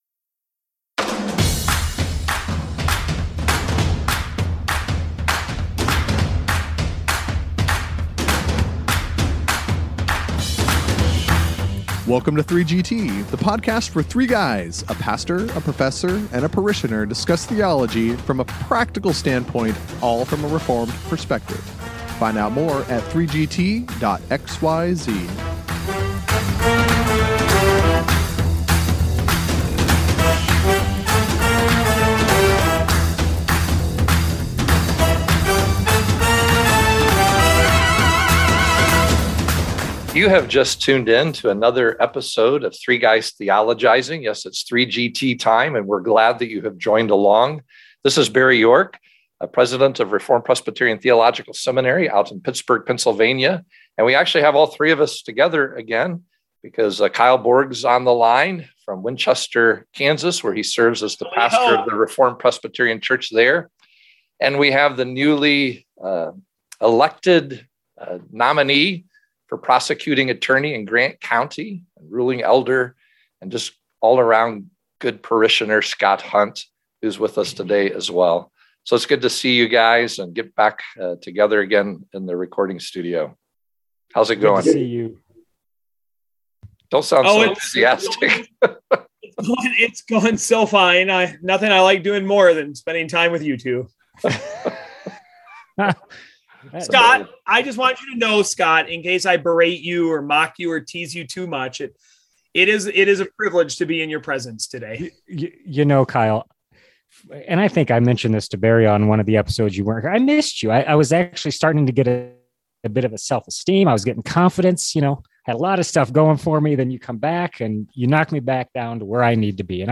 So be sure to listen to this episode, and be sure to listen for our special guest at the very end of the podcast!